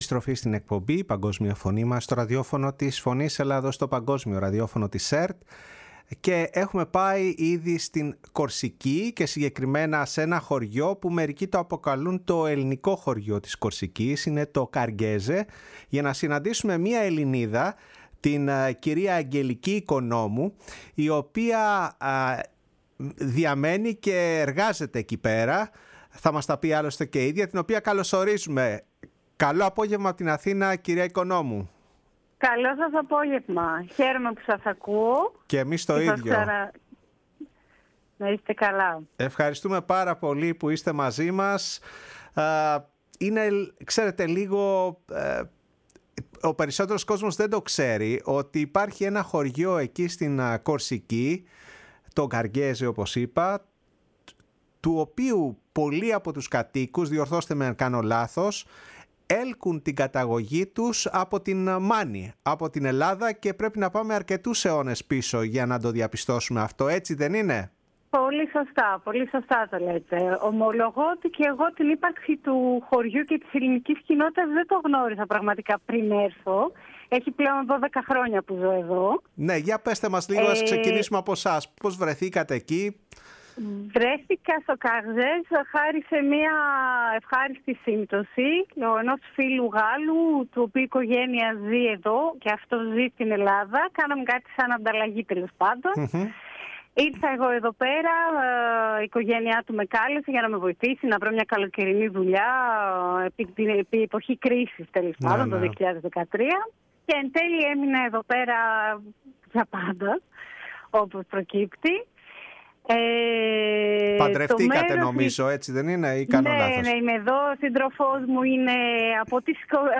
στο ραδιόφωνο της Φωνής της Ελλάδας